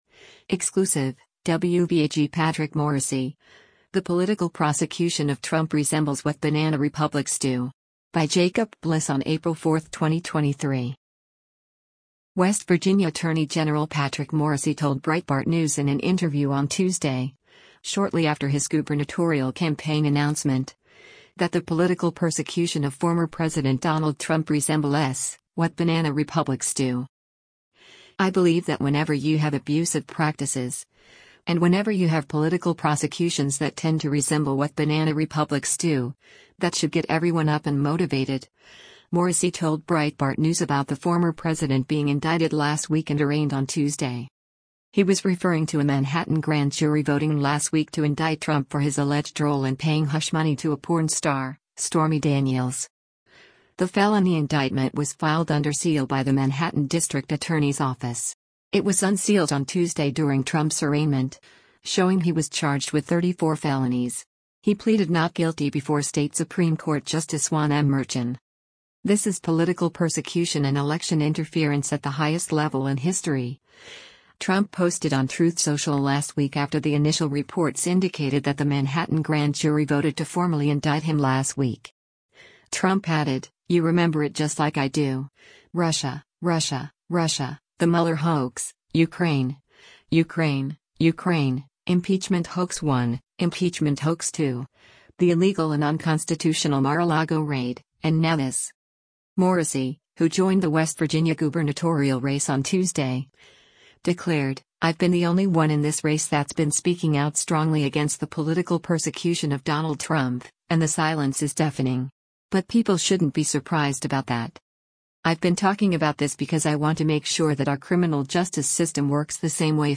West Virginia Attorney General Patrick Morrisey told Breitbart News in an interview on Tuesday, shortly after his gubernatorial campaign announcement, that the “political persecution” of former President Donald Trump “resemble[s] what Banana Republics do.”